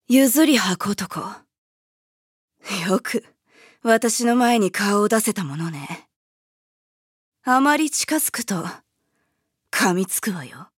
음성 대사